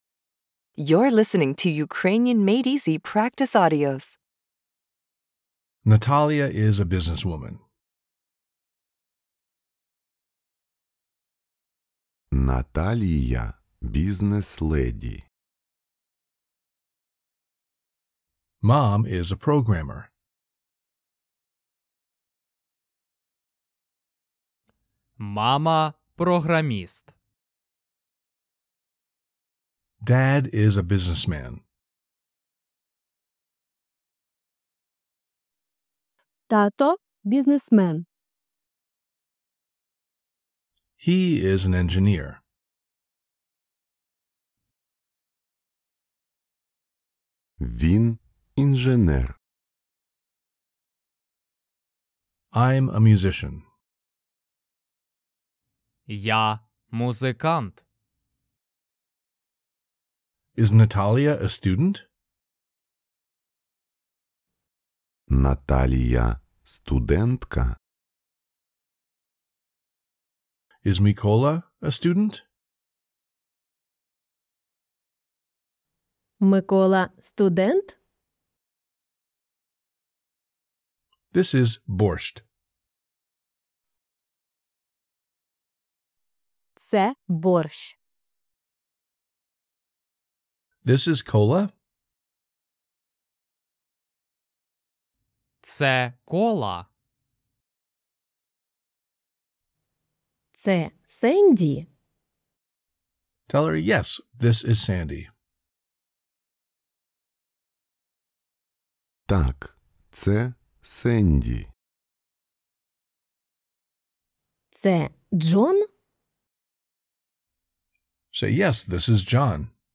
🎧 Listening Practice Audio